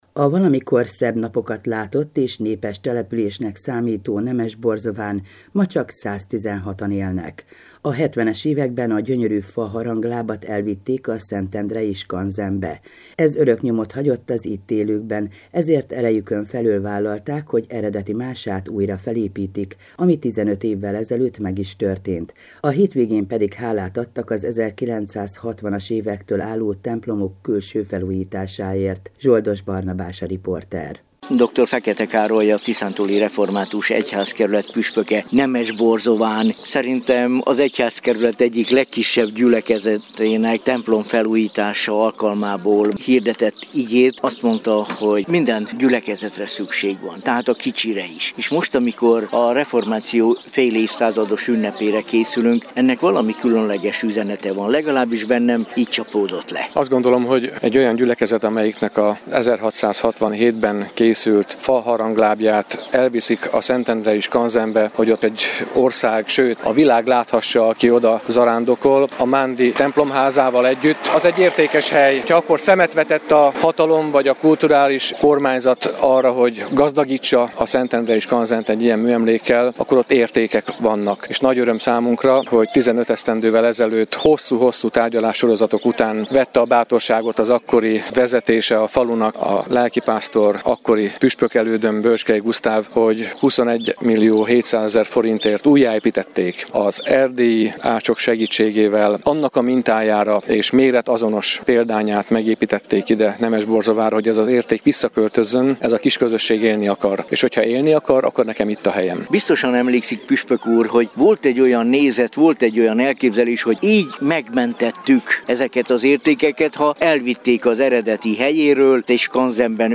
Hálaadó istentisztelet Nemesborzován - hanganyaggal